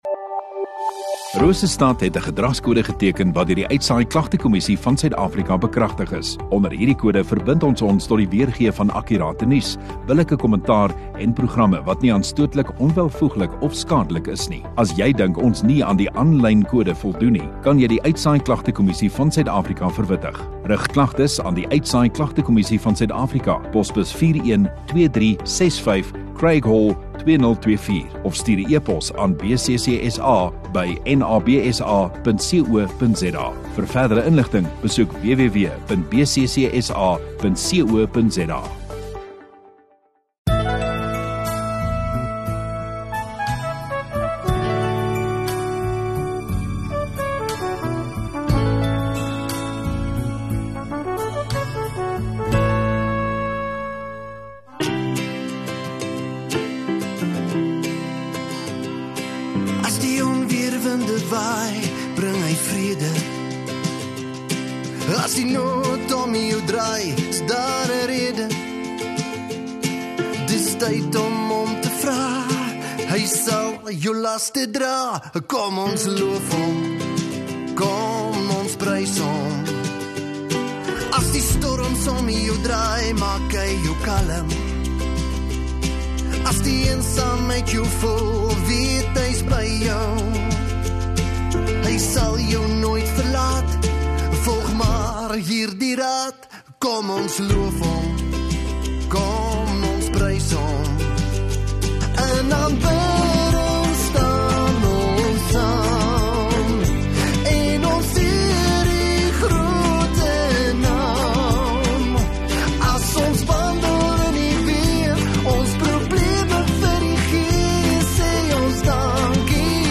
7 Sep Sondagoggend Erediens